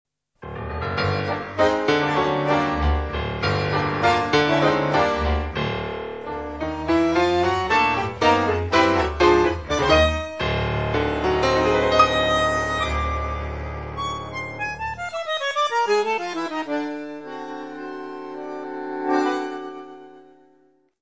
Bandoneón
piano
contrabaixo
Music Category/Genre:  World and Traditional Music